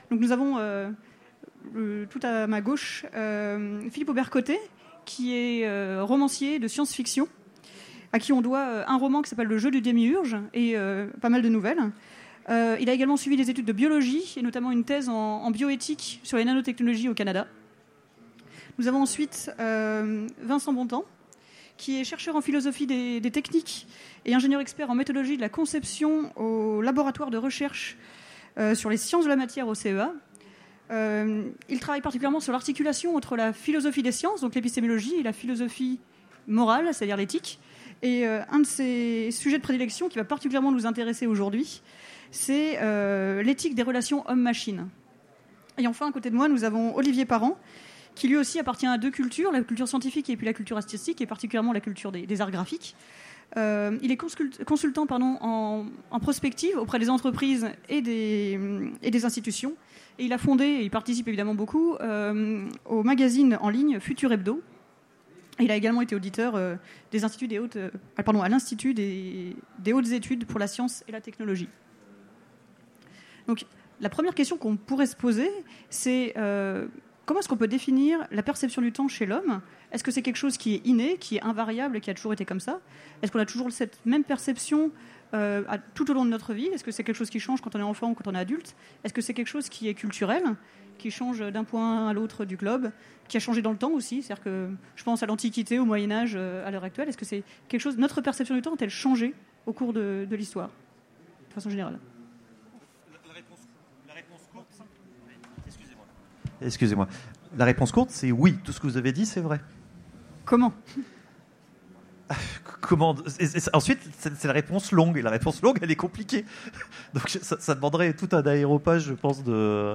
Utopiales 2017 : Conférence Le temps des machines et le temps de l’humain
Il manque les premières secondes d'introduction, nos excuses.